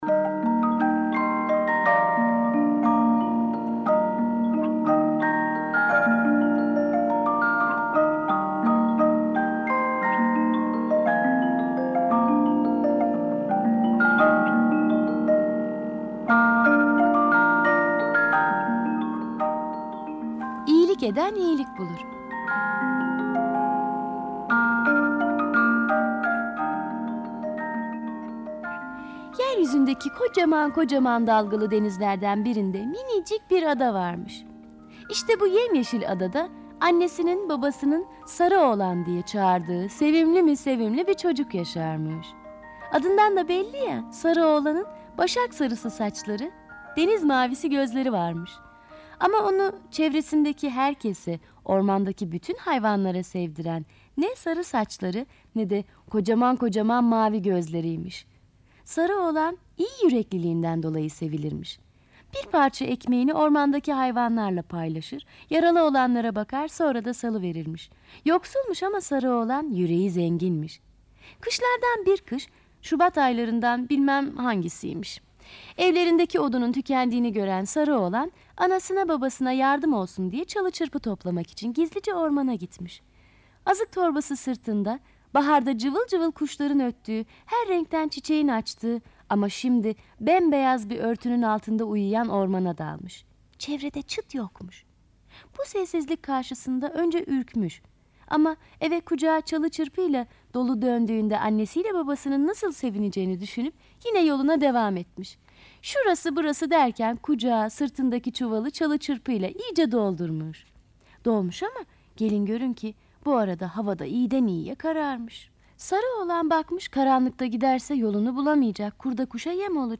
Kategori Sesli Çocuk Masalları